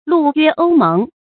鷺約鷗盟 注音： ㄌㄨˋ ㄩㄝ ㄡ ㄇㄥˊ 讀音讀法： 意思解釋： 謂與鷺、鷗相約結盟。比喻隱居者的生活。